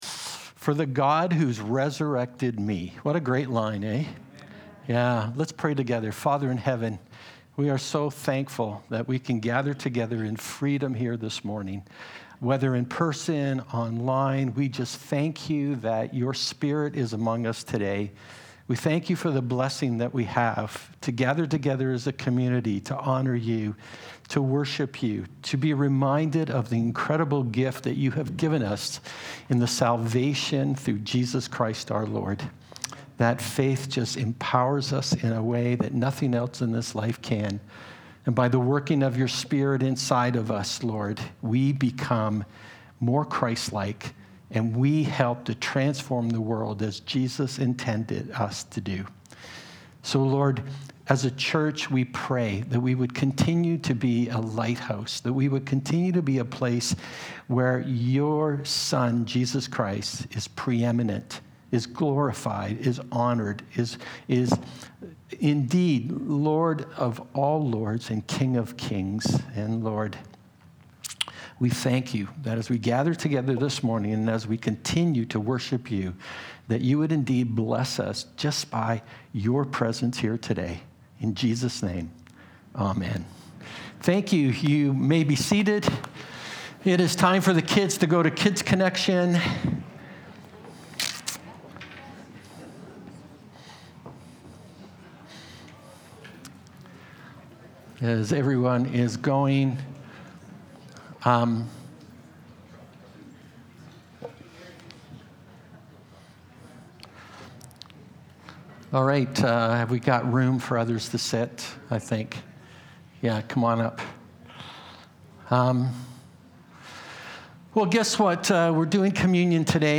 In this Communion message, we look at how Jesus speaks into every dimension of our lives.